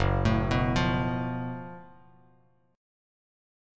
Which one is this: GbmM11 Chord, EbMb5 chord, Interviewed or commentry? GbmM11 Chord